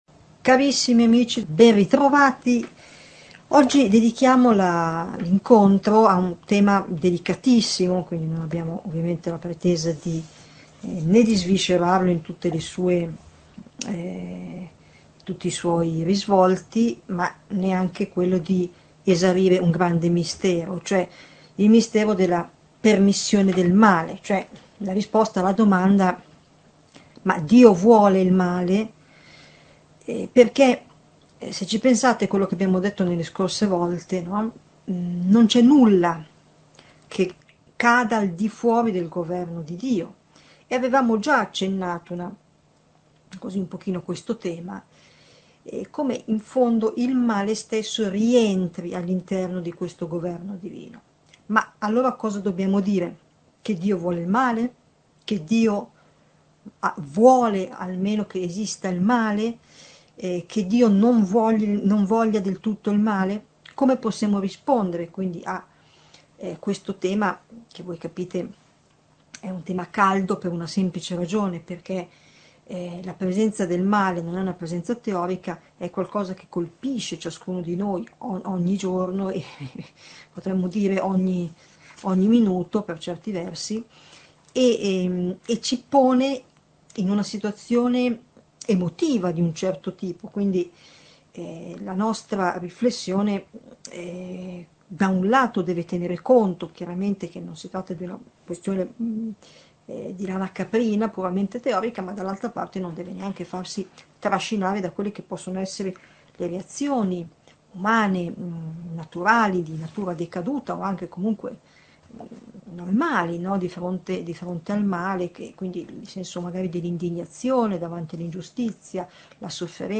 Catechesi adulti